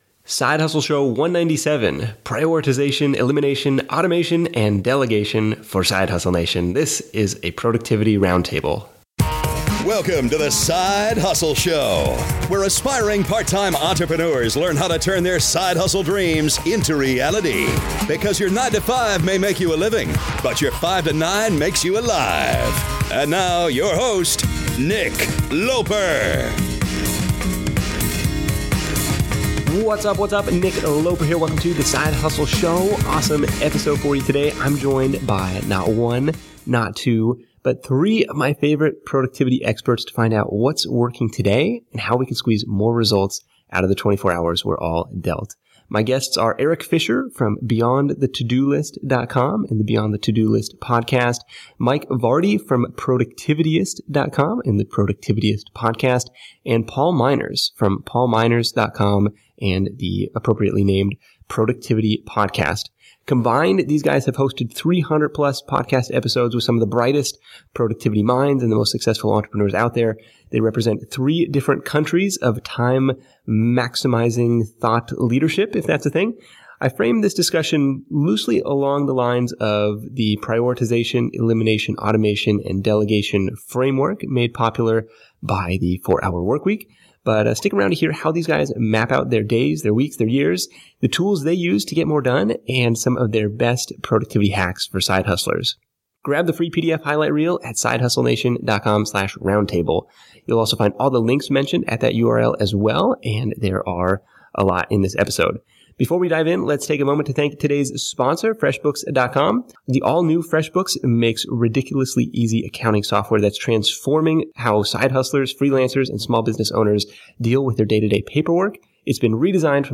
Prioritization, Elimination, Automation, and Delegation for Side Hustle Nation: A Productivity Roundtable